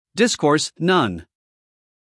英音/ ˈdɪskɔːs / 美音/ ˈdɪskɔːrs /